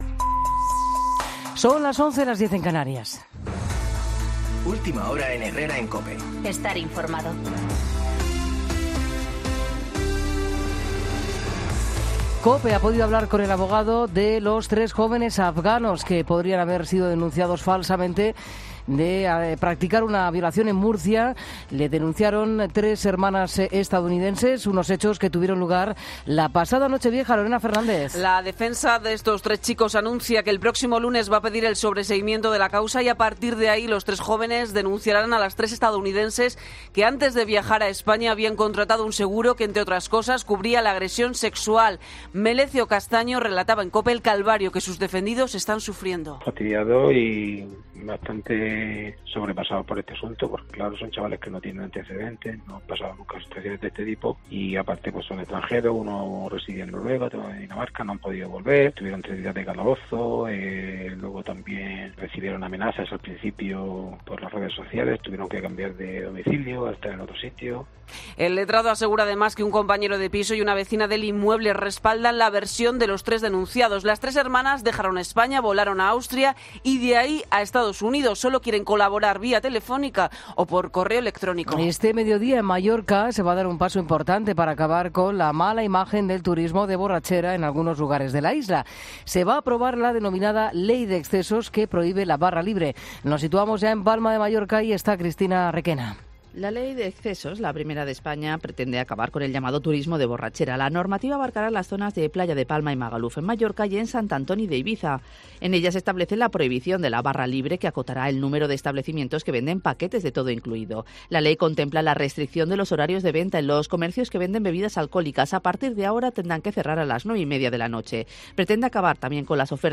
Boletín de noticias COPE del 17 de enero a las 11:00